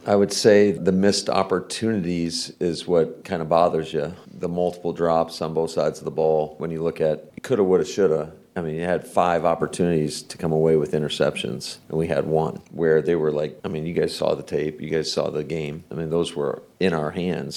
(LEARFIELD) – There was some good news coming out of Packers coach Matt LaFleur’s meeting with the media on Monday.